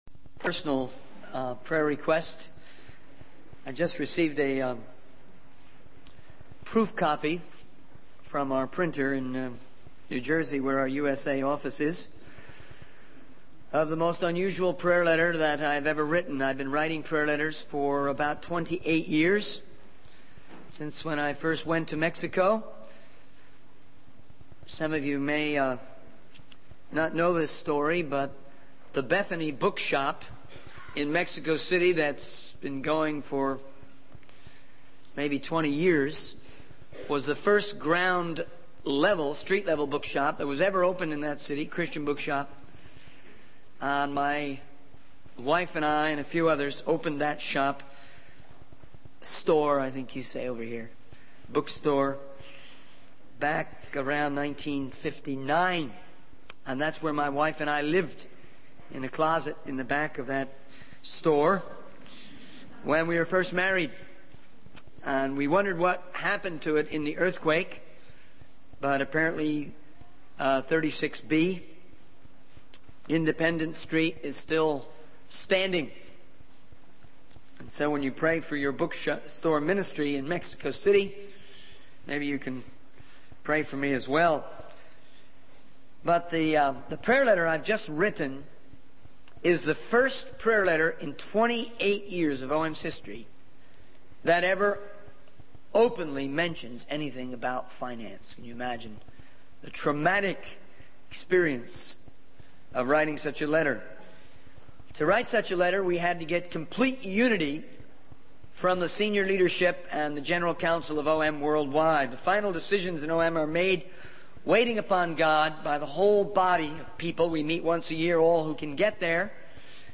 In this sermon, the speaker addresses a church audience and emphasizes the potential they have to impact the world for Jesus Christ. He highlights five key factors that can help them realize this potential: prayer, intensive study and research, discipline, winning and mobilizing others, and obedience to the whole council of God.